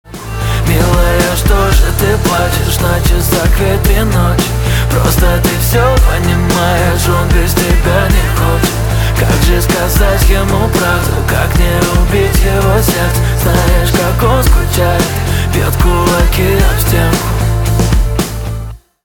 поп
гитара , барабаны
чувственные
грустные , печальные